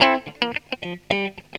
GTR 64 GM.wav